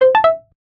notification_sounds
loud tintong.ogg